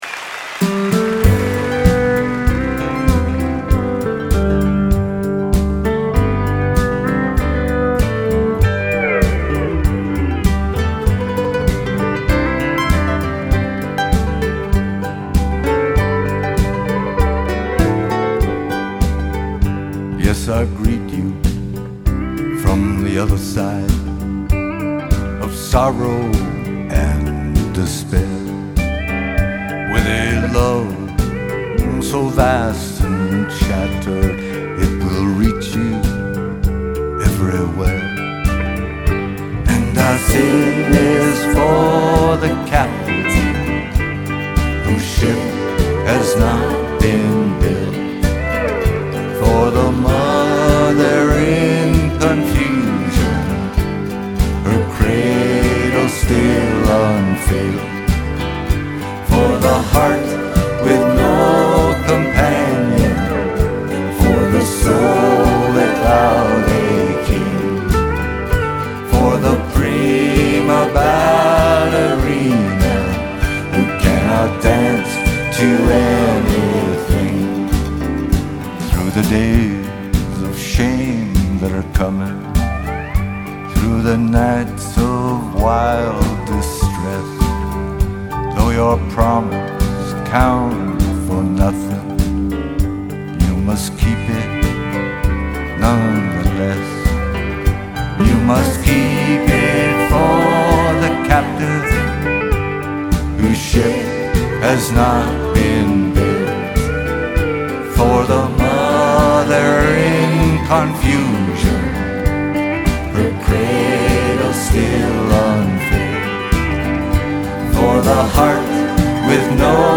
Live Nov 2